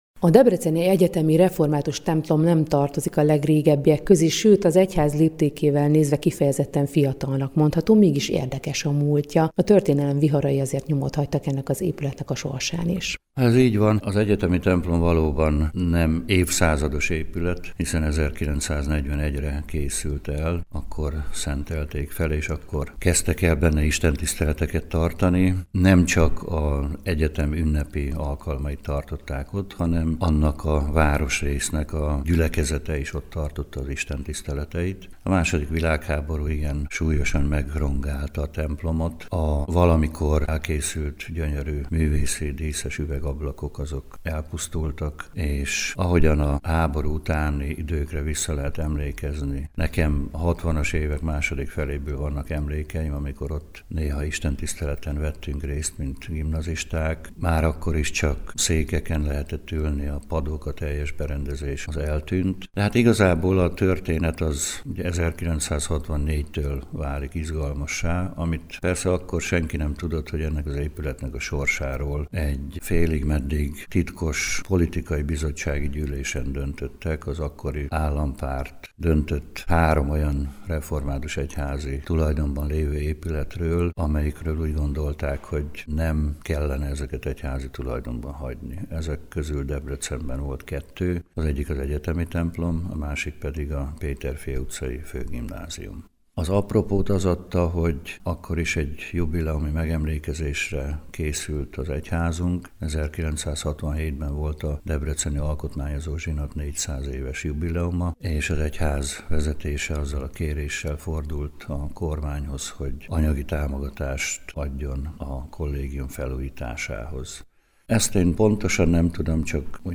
Egyetemi templom - interjú Bölcskei Gusztávval - hanganyaggal